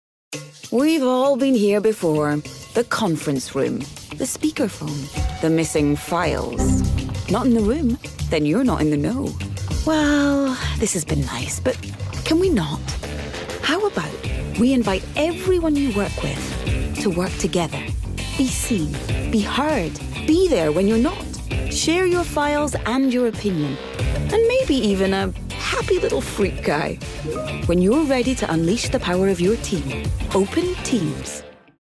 30's Scottish, Warm/Reassuring/Bright